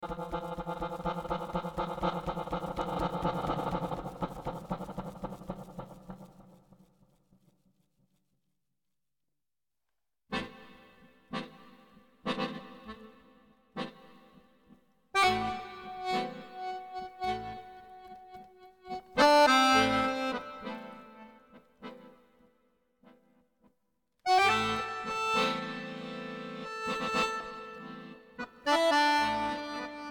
In this CD you find a completly improvised performance.